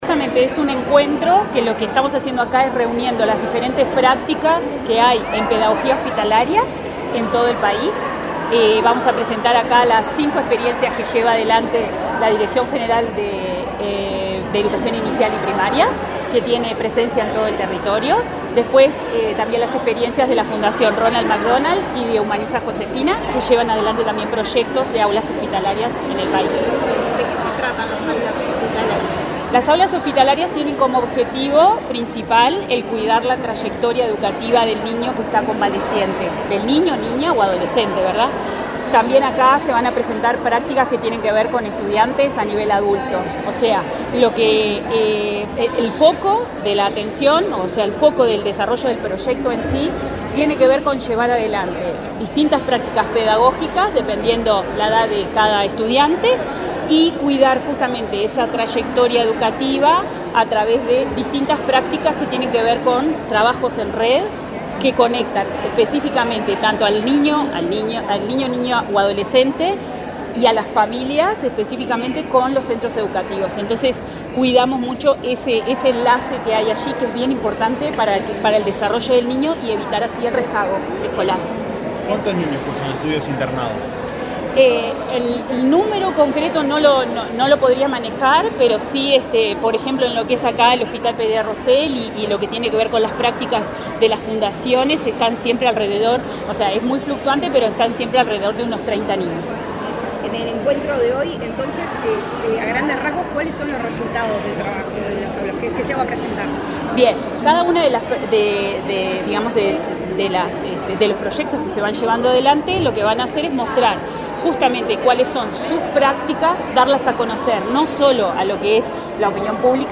Declaraciones de la directora sectorial de Salud de ANEP, Patricia Odella
Declaraciones de la directora sectorial de Salud de ANEP, Patricia Odella 22/11/2024 Compartir Facebook X Copiar enlace WhatsApp LinkedIn La directora sectorial de Salud de la Administración Nacional de Educación Pública (ANEP), Patricia Odella, dialogó con la prensa, antes de participar en la jornada Pedagogía Hospitalaria: Una Mirada más allá de las Aulas, realizada este viernes 22 en la Torre Ejecutiva.